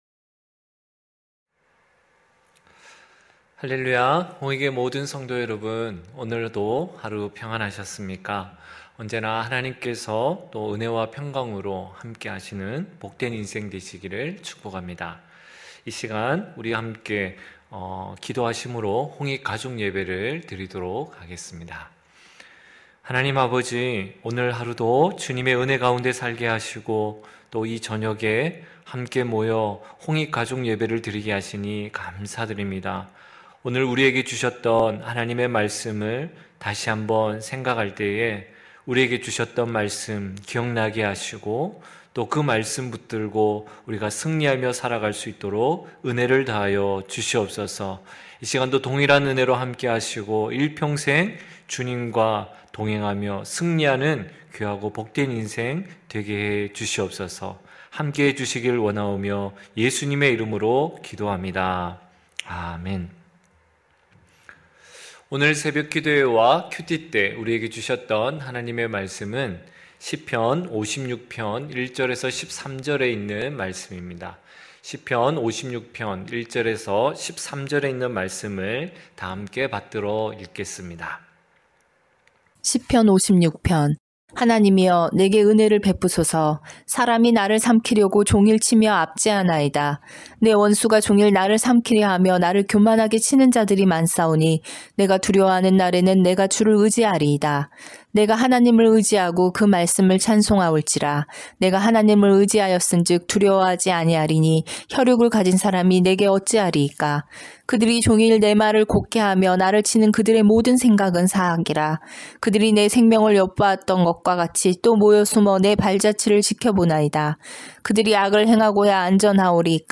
9시홍익가족예배(3월6일).mp3